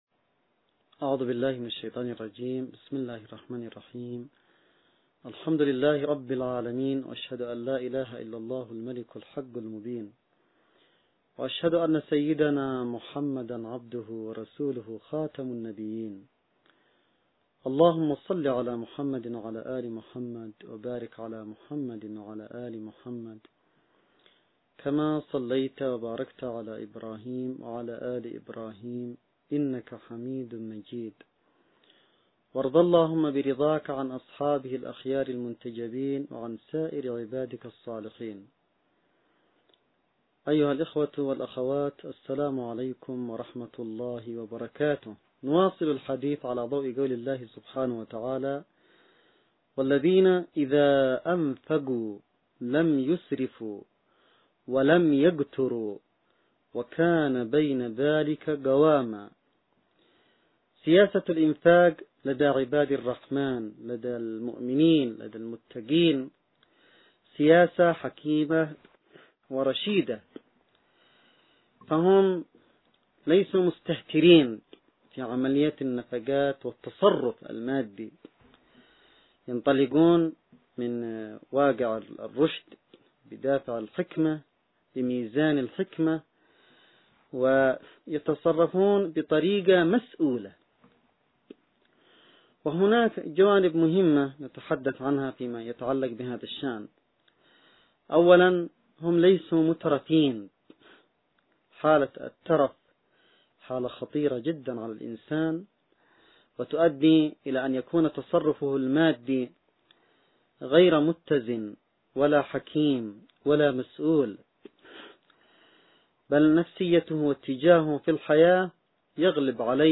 (نص+فيديو+أستماع) لـ محاضرة مواصفات المؤمنين 5 – المحاضرة الرمضانية الثالثة والعشرون للسيد عبدالملك بدرالدين الحوثي 1439 هـ .
محاضرة_مواصفات_المؤمنين_5_المحاضرة.mp3